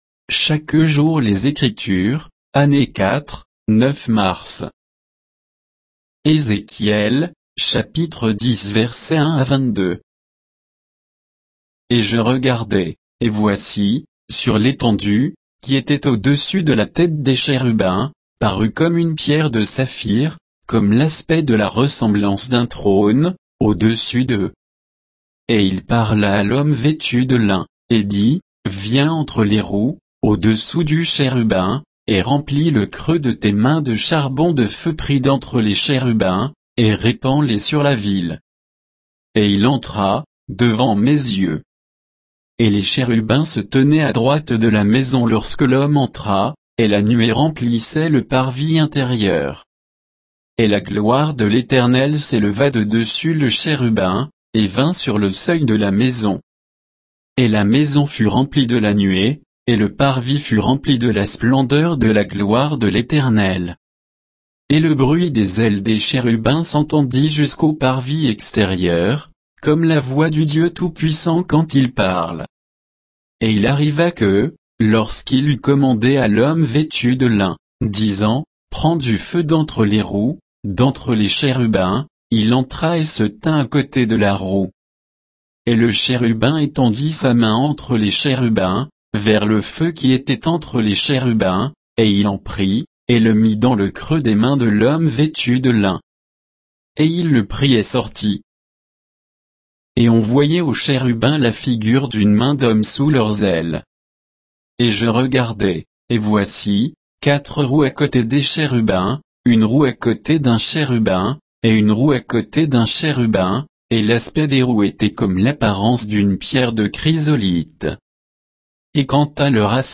Méditation quoditienne de Chaque jour les Écritures sur Ézéchiel 10